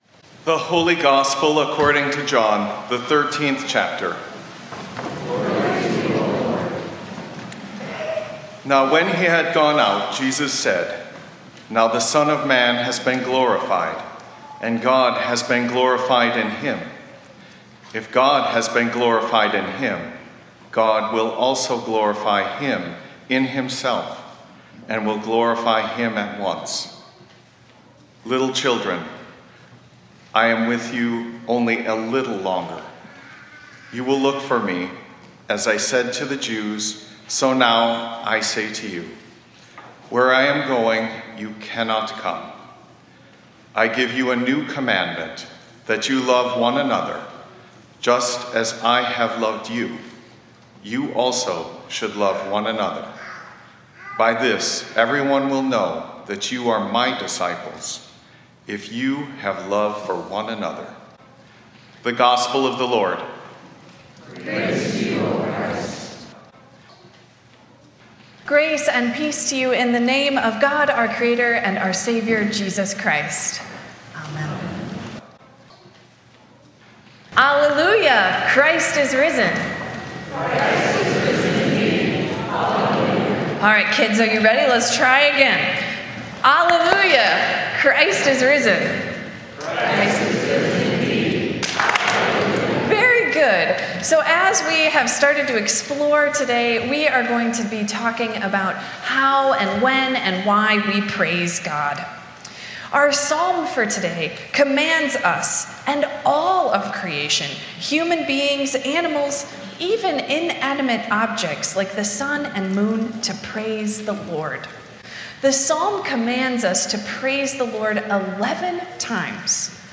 Includes sermons from our Sunday morning 9:45 worship services.